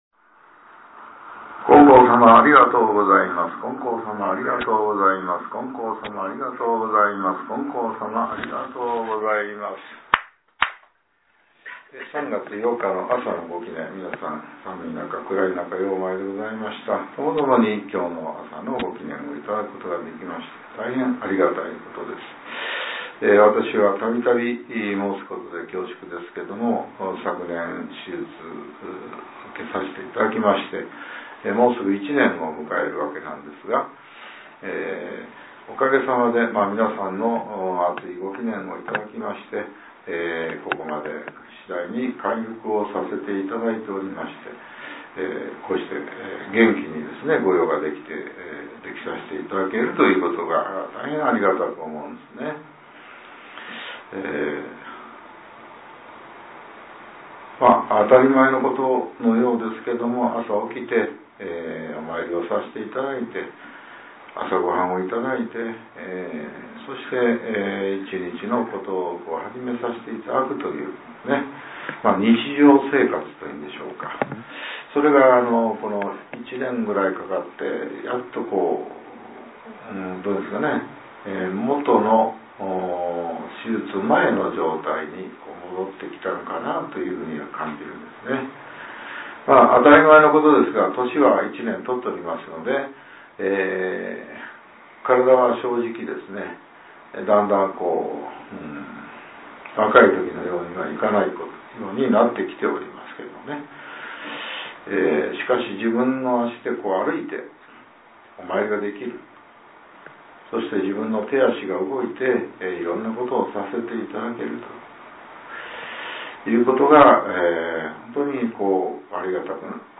令和７年３月８日（朝）のお話が、音声ブログとして更新されています。